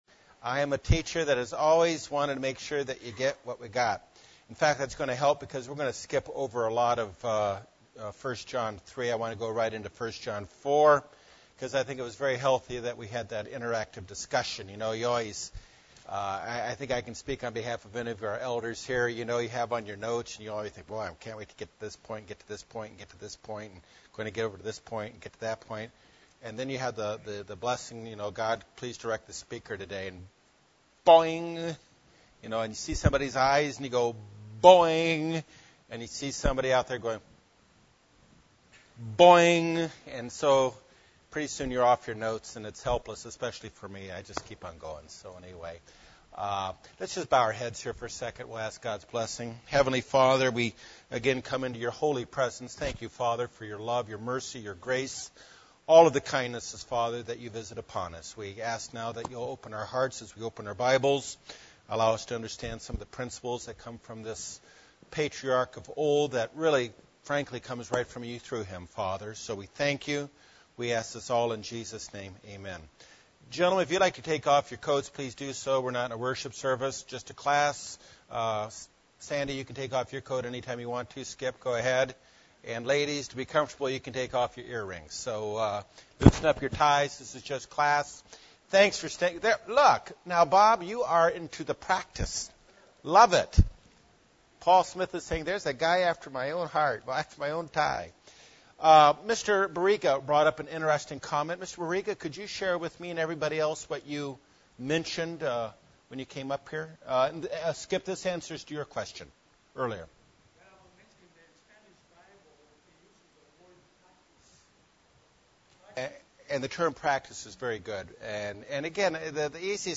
Continuing Bible study on the book of 1 John. 1 John 4 looks at the ideas that we should believe in the name of the Father's son - Jesus Christ - and love each other. The Key to understanding 1 John is “abide”.